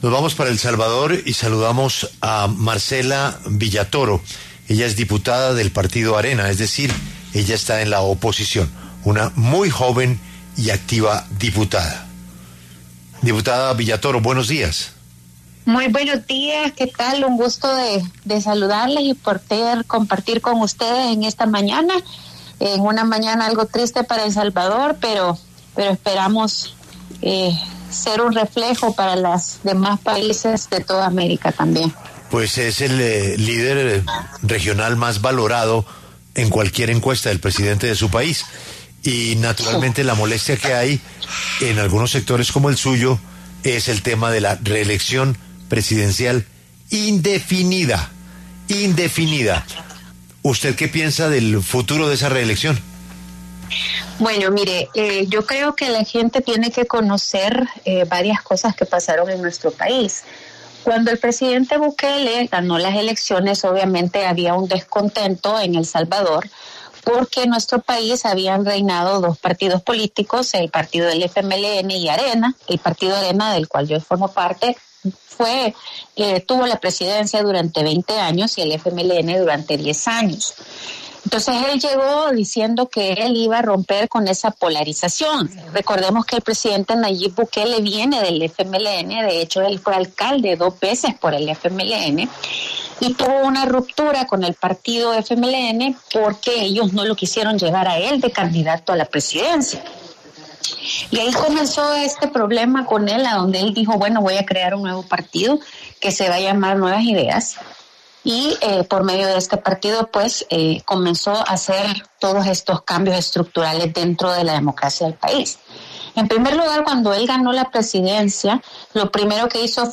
En diálogo con la W Radio, la diputada Marcela Villatoro del partido opositor Alianza Republicana Nacionalista (Arena), criticó la reforma y dijo que los legisladores “han matado la Constitución”.